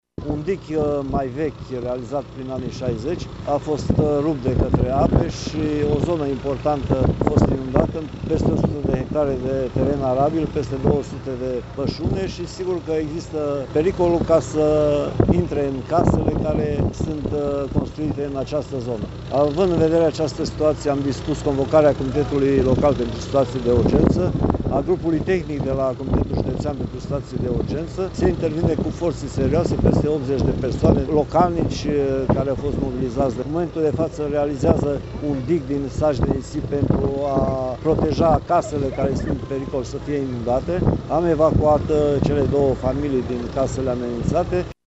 Prefectul județului Mureș, Mircea Dușa, se află la Sânpaul, unde evaluează situația provocată de inundațiile din zonă:
stiri-22-mai-prefect.mp3